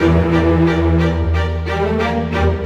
Rock-Pop 07 Strings 03.wav